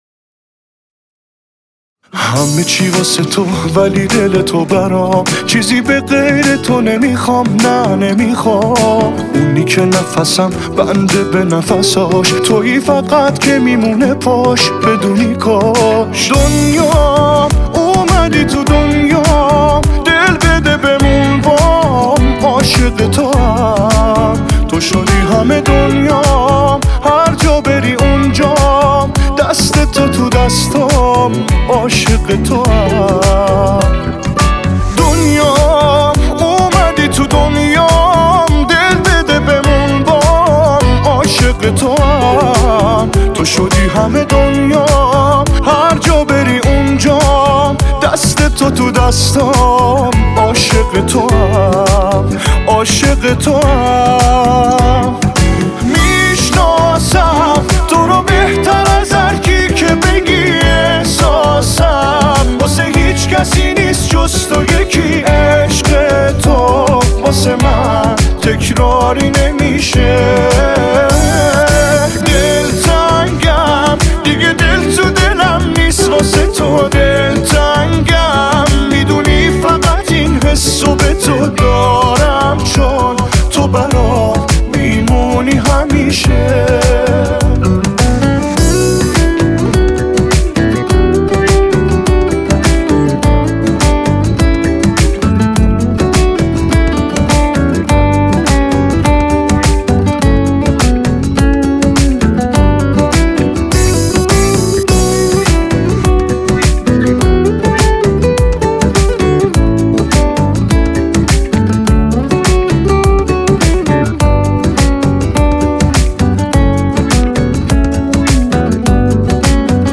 اهنگ زیبا و احساسی